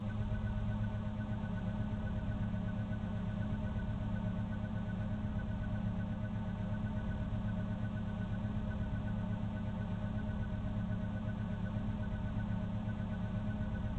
PortalNonMagical 2.wav